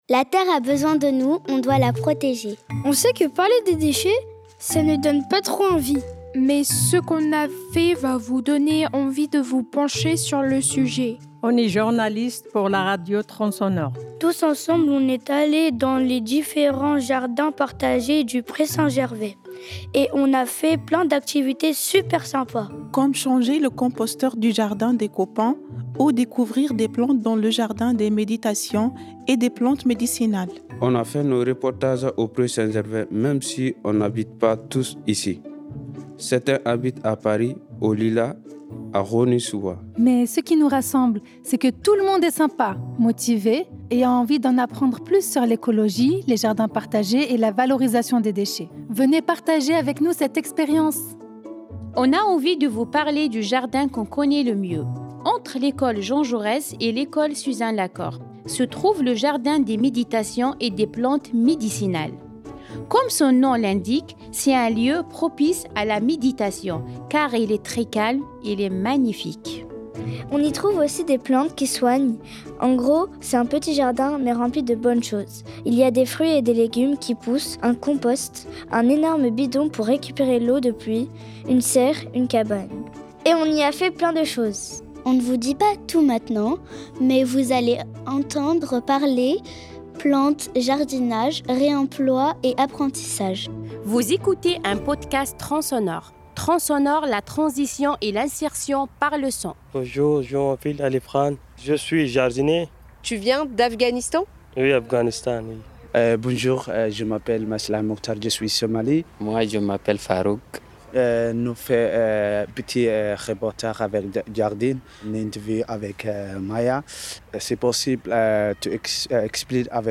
Nous sommes des enfants, des jeunes et des adultes qui habitons au Pré Saint-Gervais, mais pas que !
Découvrez nos reportages made in Le Pré !
Vous allez nous entendre parler plantes, jardinage, réemploi et apprentissage.
Accompagnez-nous dans notre voyage sonore dans le jardin des méditations et des plantes médicinales.